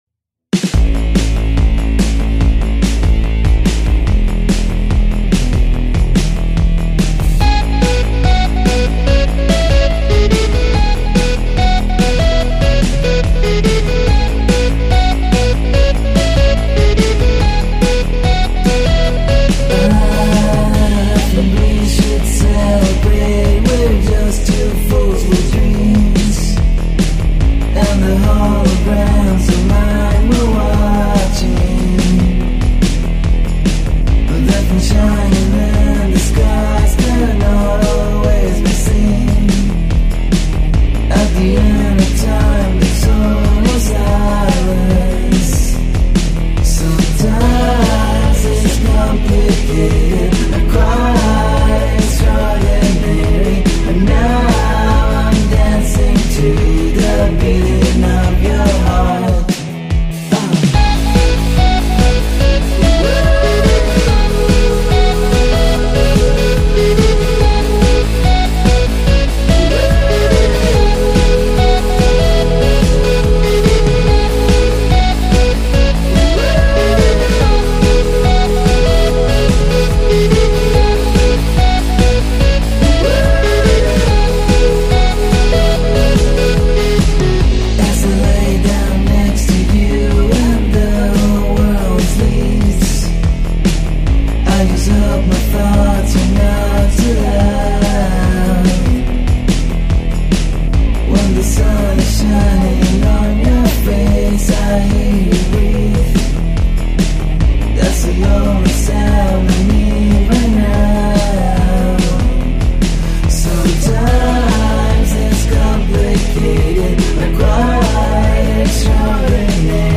Houston Texas dream-pop band